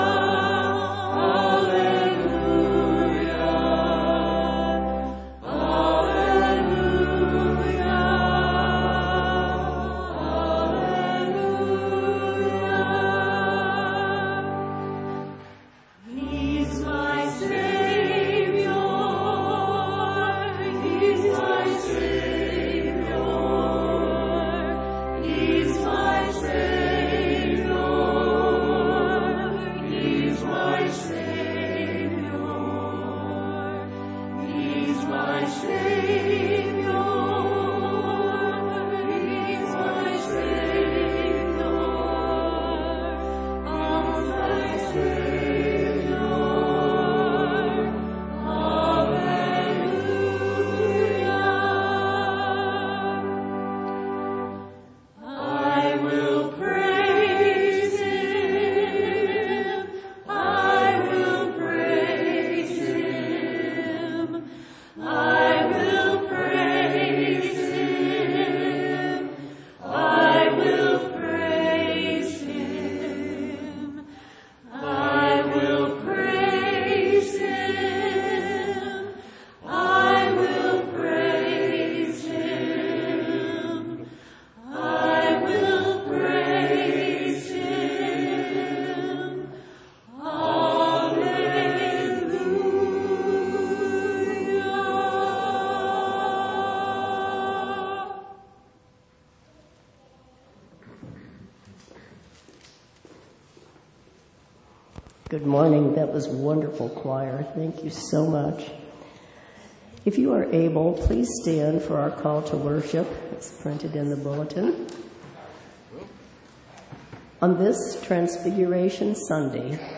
Sermons Archive - Faith Community United Methodist Church - Xenia, OH